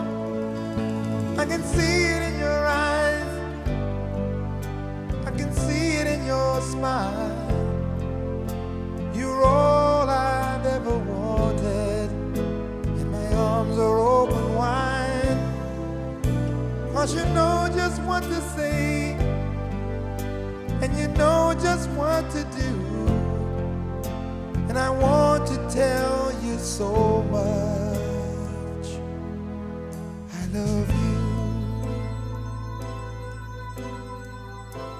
Жанр: Поп / R&b / Рок / Соул